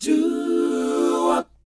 DOWOP G CD.wav